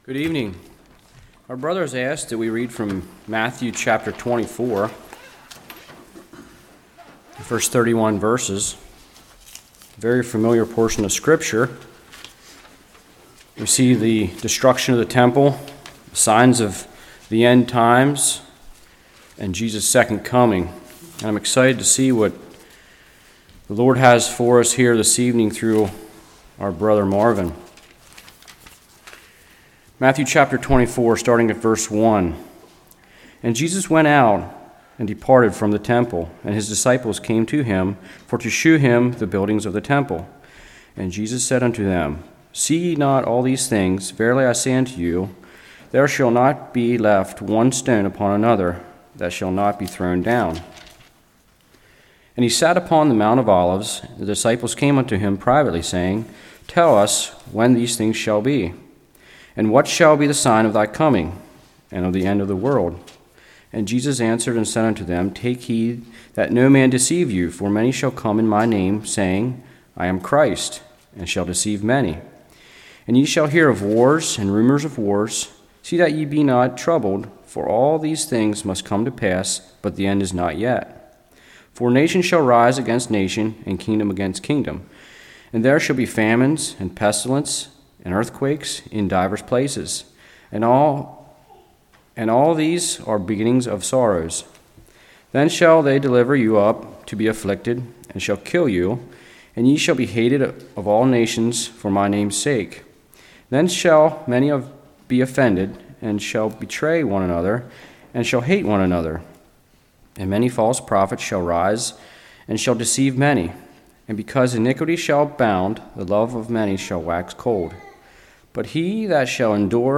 Matthew 24:1-31 Service Type: Evening The Deception of Satan How does deception start?